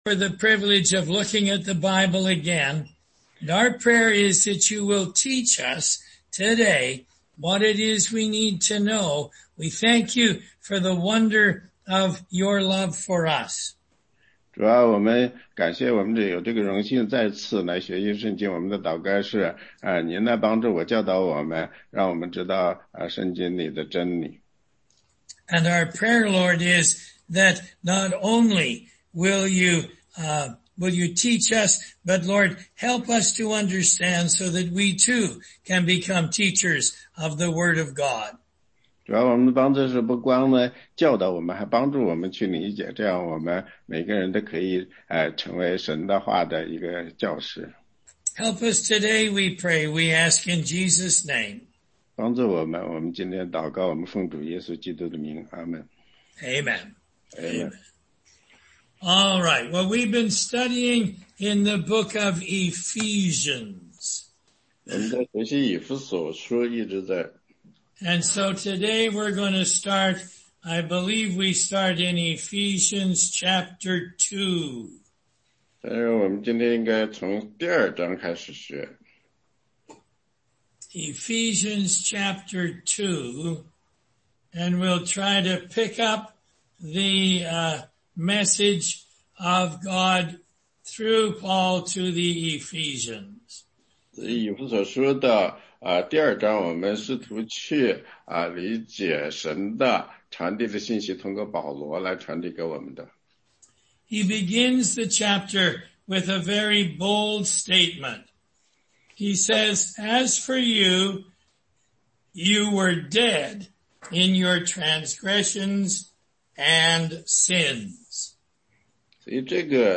16街讲道录音 - 以弗所书2章：神救我们，为的是把他的恩典显给后来的世代看
答疑课程